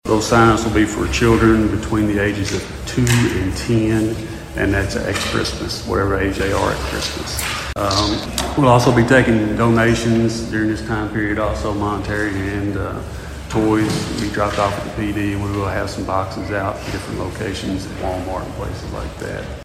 During Monday night’s meeting, Chief King informed City Council members about the toy drive, emphasizing that the deadline for submitting applications and donations at the police department is December 16.